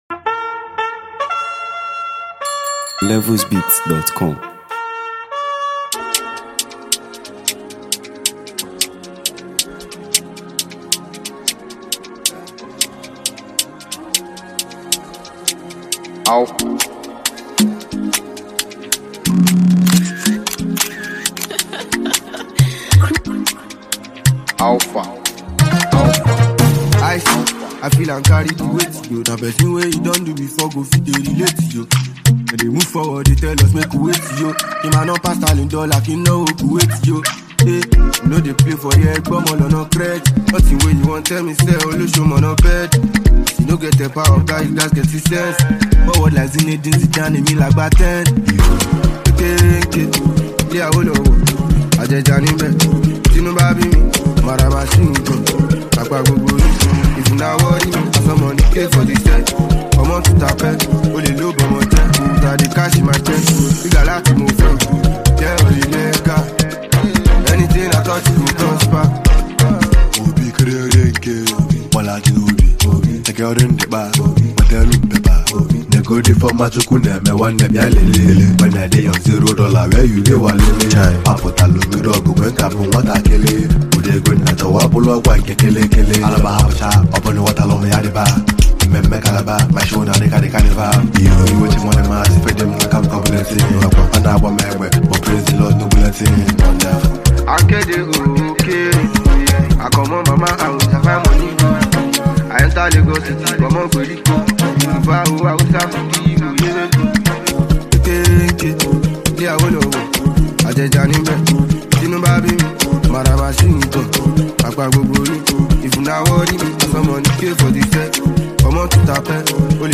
Nigeria Music 2025 2:26
This fresh version injects new energy into the original